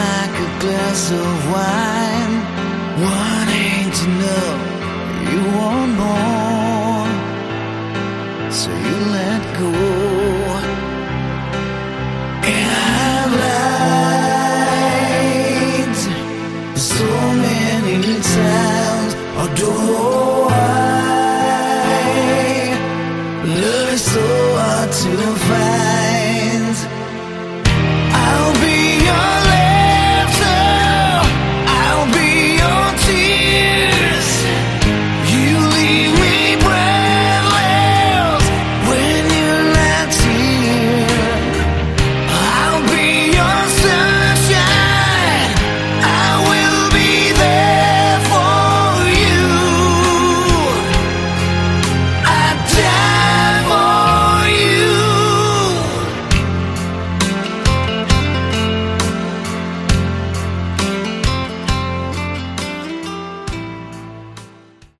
Category: Melodic Hard Rock
Vocals, Bass, Guitar, Keyboards
Drums, Backing Vocals
buenas baladas.